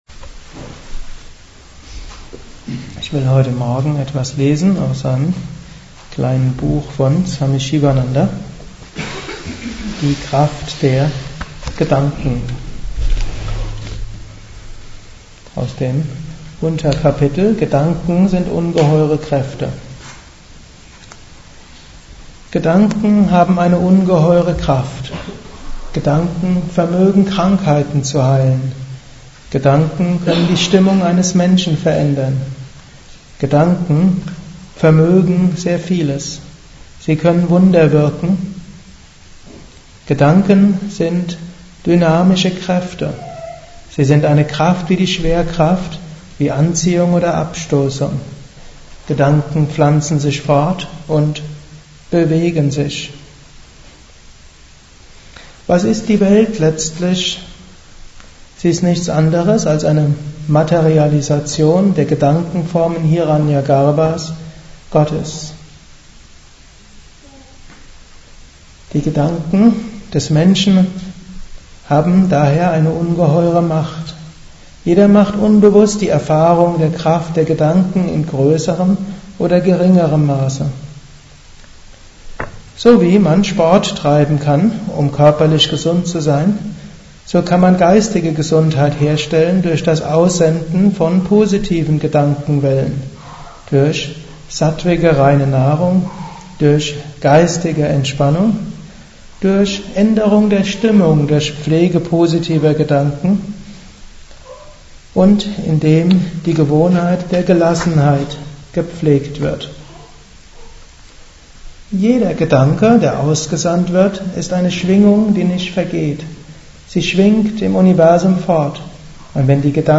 Gedanken haben große Kraft - Kurzvortrag als mp3-Datei - Yoga Vidya Blog - Yoga, Meditation und Ayurveda